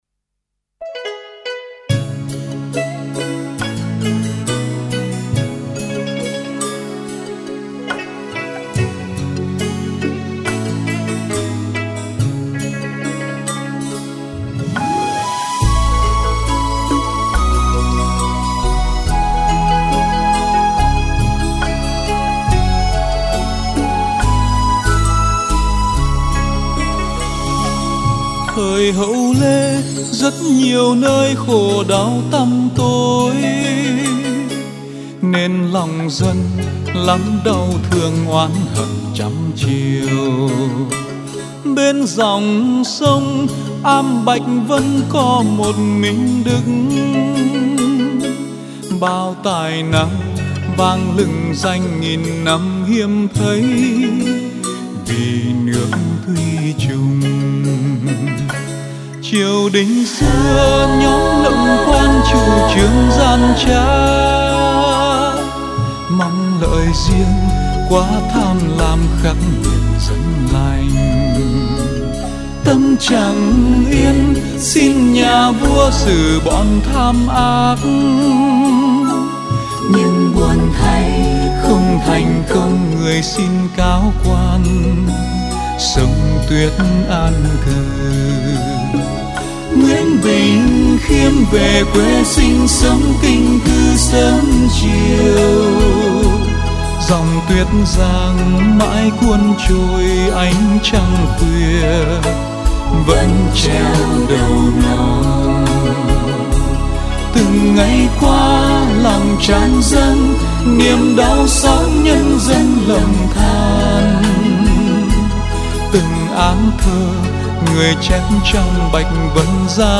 CA KHÚC